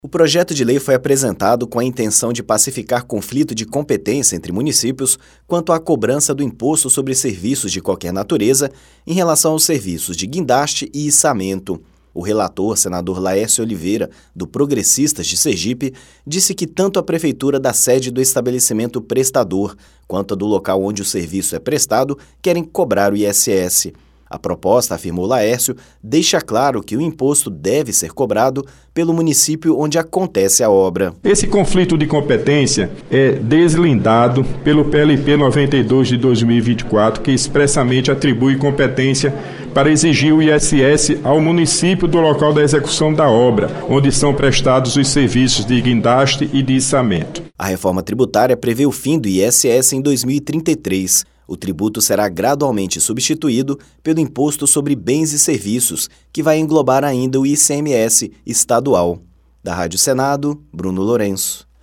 O relator, senador Laércio Oliviera (PP-SE), explica que proposta surgiu para resolver impasse entre cidades que são as sedes das empresas de guindaste e as prefeituras onde os serviços são prestados.